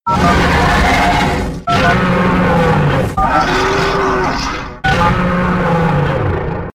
We used Audacity® to combine the “Monster” soundtrack left and right audio channels into a single monaural track.  We added a pair of DTMF tones to the right audio channel corresponding to each sound burst,
From the debug information, we can see the right audio track of “Monster DTMF” soundtrack contains DTMF tones 0, 1, … 7.
Monster DTMF.mp3